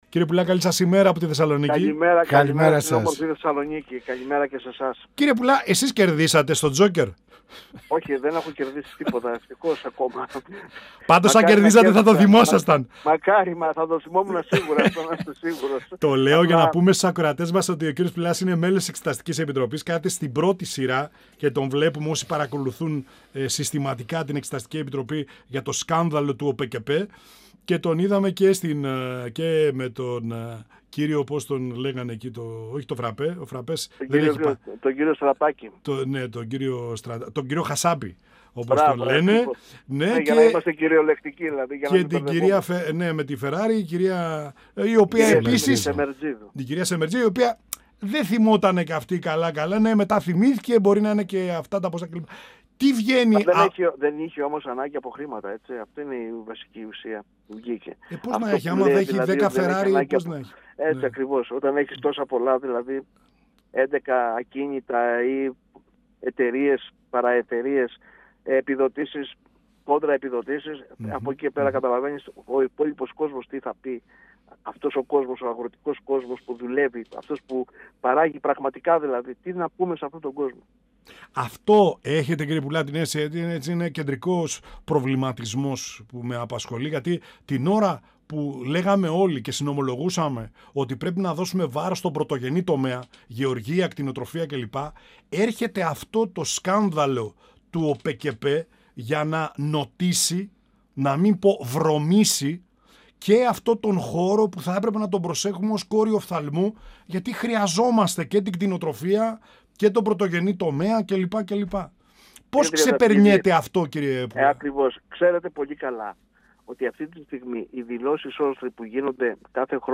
Στις αγροτικές κινητοποιήσεις και τα αγροτικά μπλόκα,  στις ενδεχόμενες αναγκαίες συνεργασίες μεταξύ κομμάτων, μετά τις επόμενες βουλευτικές εκλογές , καθώς και στο σκάνδαλο του ΟΠΕΚΕΠΕ αναφέρθηκε ο Βουλευτής του ΠΑΣΟΚ Ανδρέας Πουλάς, μιλώντας στην εκπομπή «Πανόραμα Επικαιρότητας» του 102FM της ΕΡΤ3.